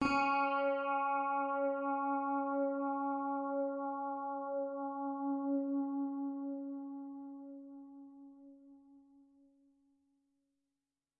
标签： CSharp5 MIDI音符-73 Korg的-Z1 合成器 单 - 注意 多重采样
声道立体声